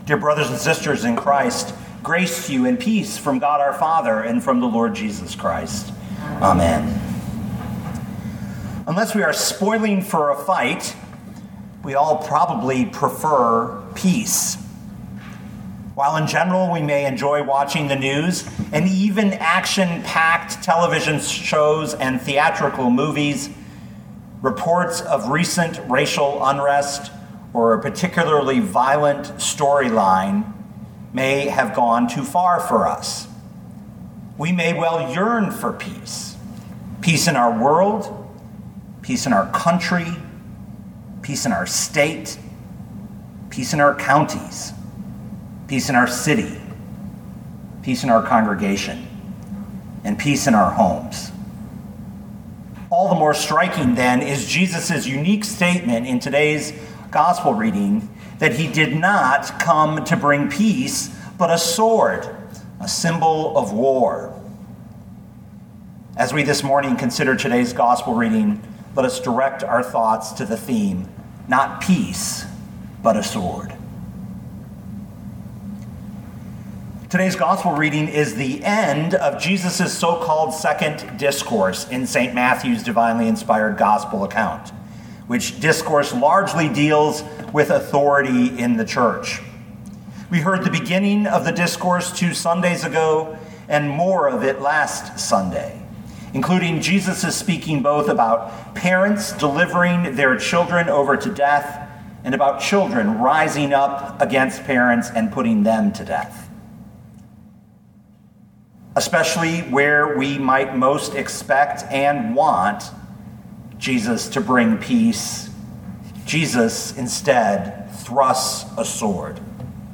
2020 Matthew 10:34-42 Listen to the sermon with the player below